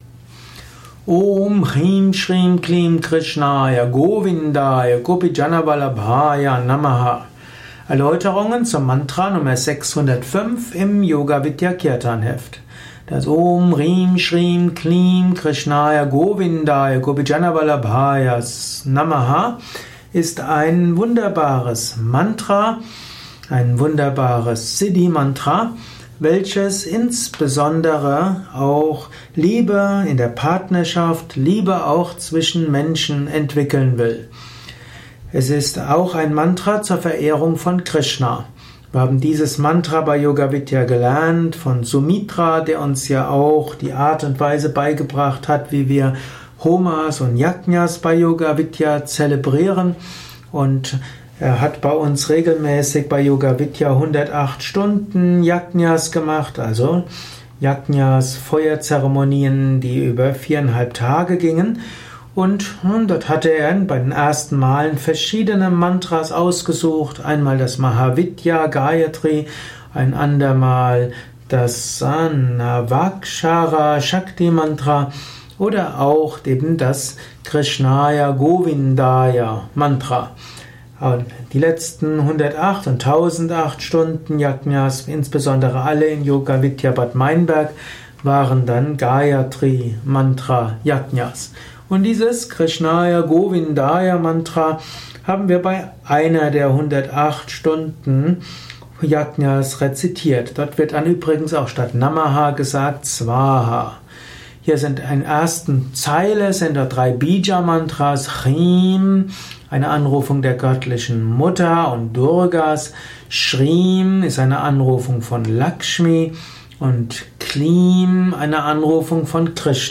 1x rezitiert.
Aufnahme im Yoga Vidya Ashram Bad Meinberg.